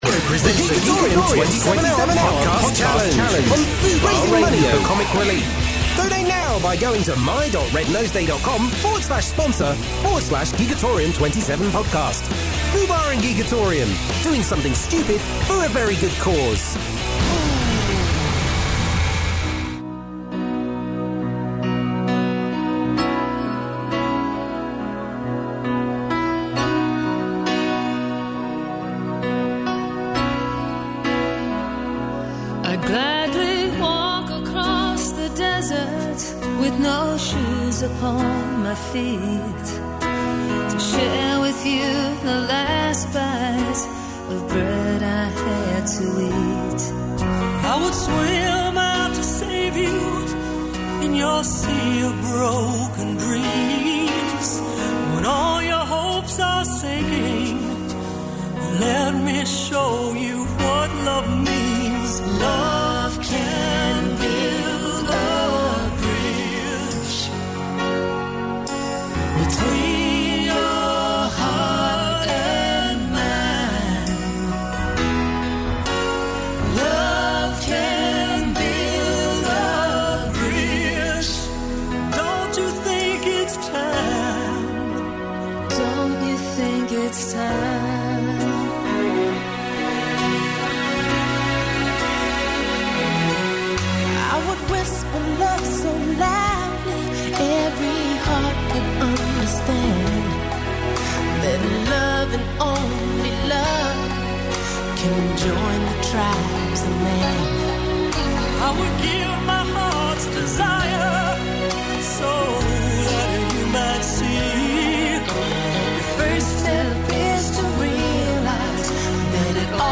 A 27 hour comedy marathon broadcast in aid of Comic Relief! Packed with games, sketches, fund raising challenges and chat.